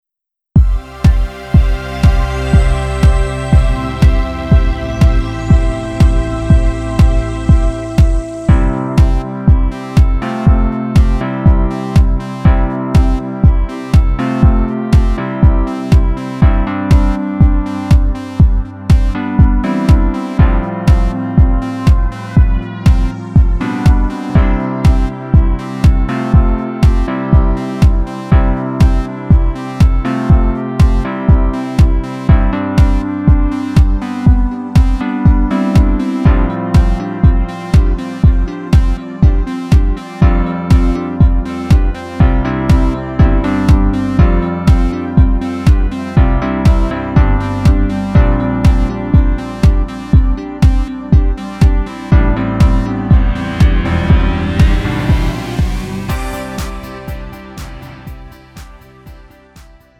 음정 원키 3:57
장르 구분 Lite MR